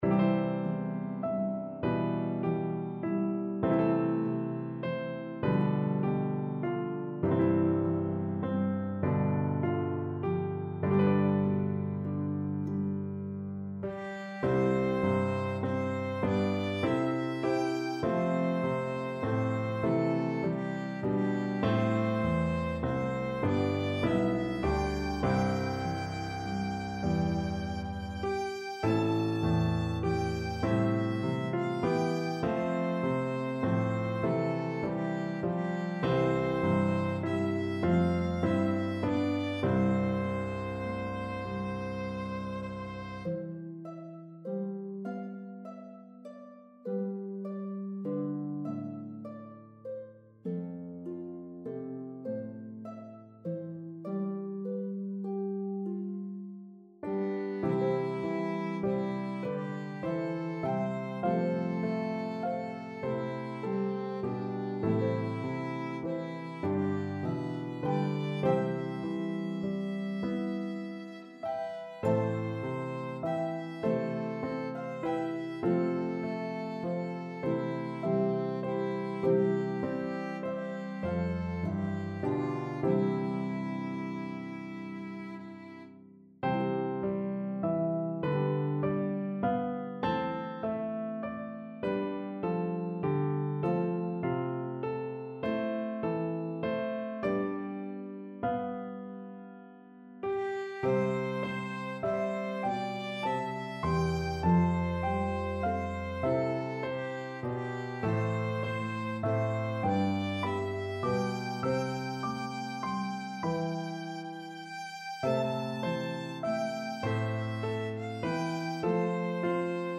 Harp, Piano, and Violin version